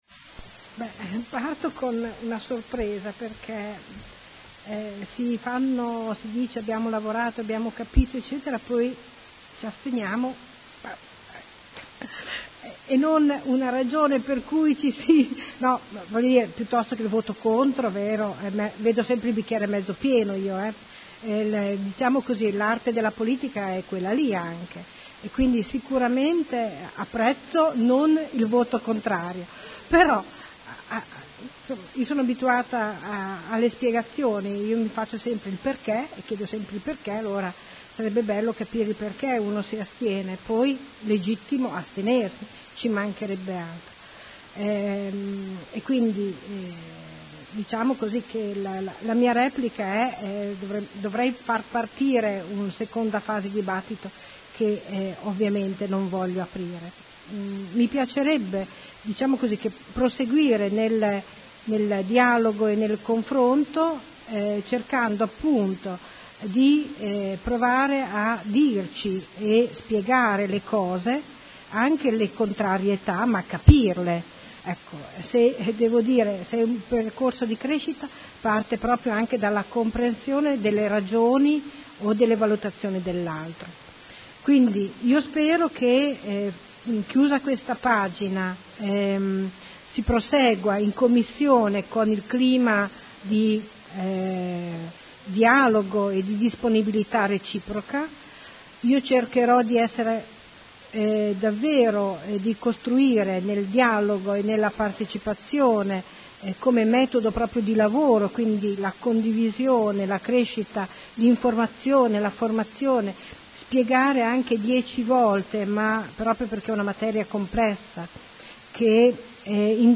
Seduta del 22 ottobre.